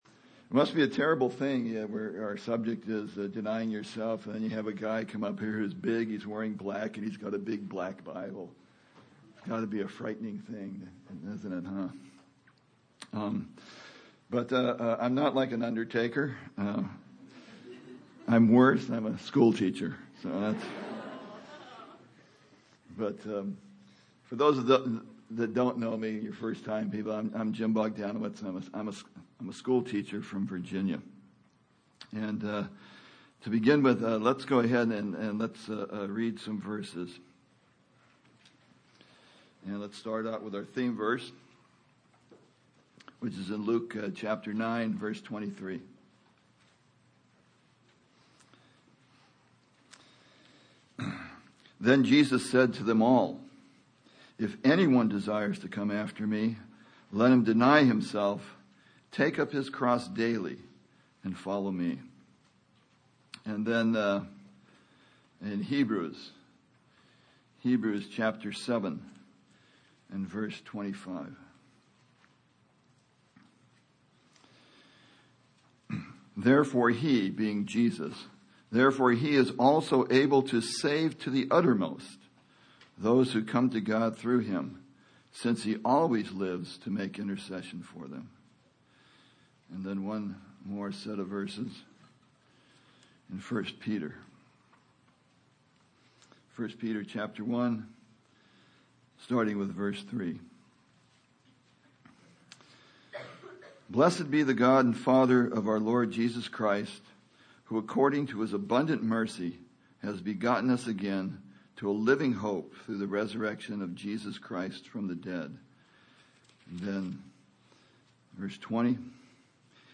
A collection of Christ focused messages published by the Christian Testimony Ministry in Richmond, VA.
Toronto Summer Youth Conference